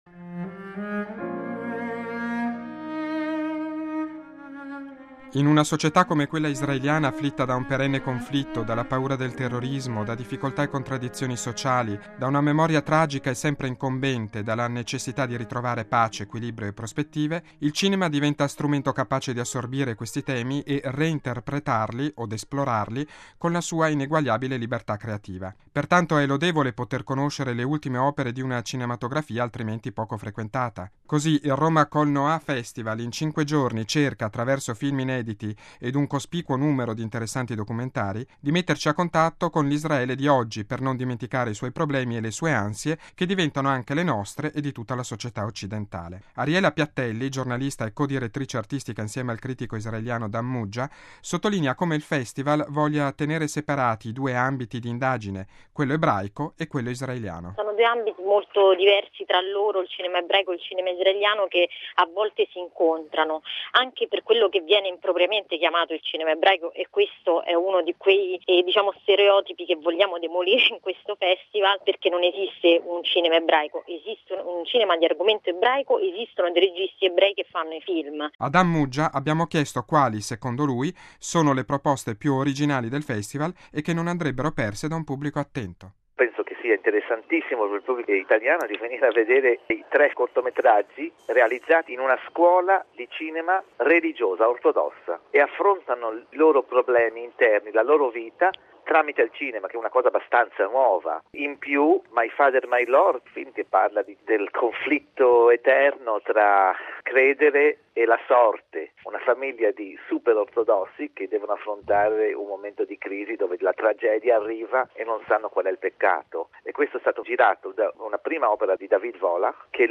◊   Si inaugura oggi a Roma, presso la Casa del Cinema, la sesta edizione del Festival dedicato a “Ebraismo e Israele nel Cinema”, in programma fino al sette novembre prossimo, che permette di avvicinare, attraverso molte e interessanti proposte cinematografiche, alcuni degli aspetti meno conosciuti della cultura e della religione ebraica e della attuale società israeliana, posta dinanzi alle sfide della pace, della giustizia e del dialogo. Servizio